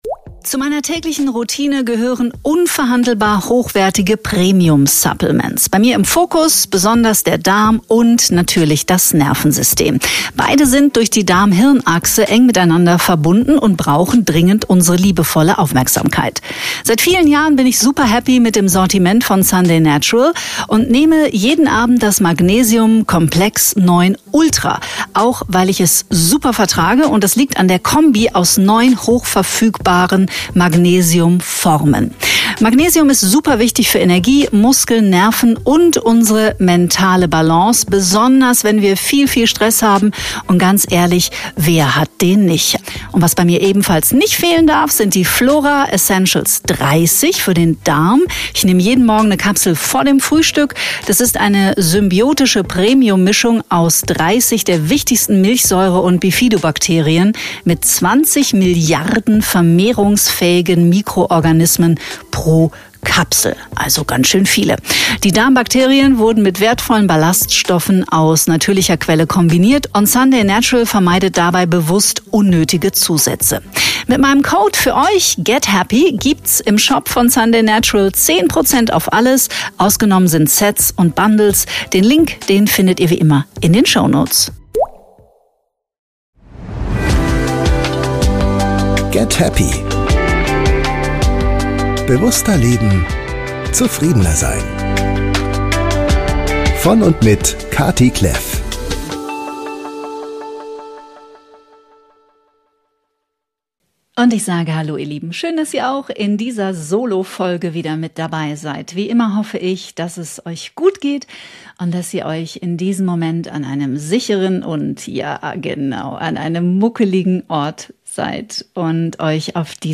In dieser Solofolge spreche ich darüber, warum wir auf unserer Heilungsreise einige Menschen verabschieden müssen. Und warum das absolut seine Berechtigung hat.